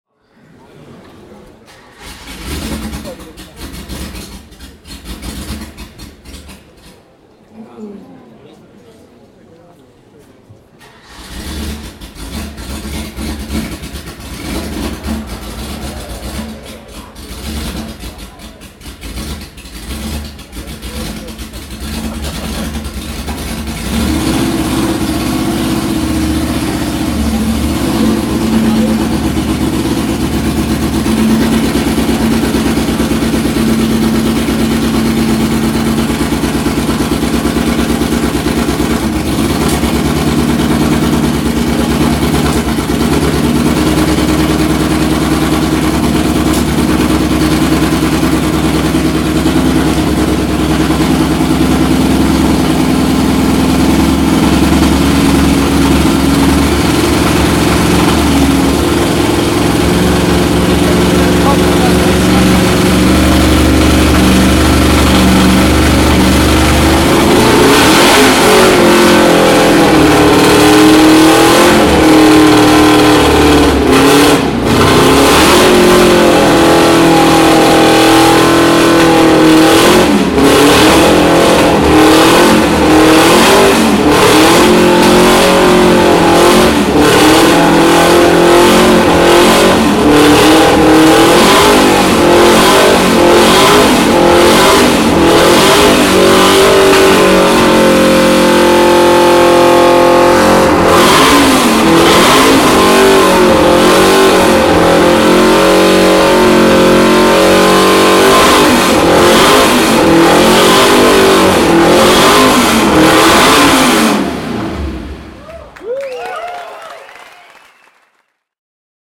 Porsche 356 B 2000 GS Carrera GT (1963) - Starten und Hochdrehen an der Porsche Sound-Nacht 2013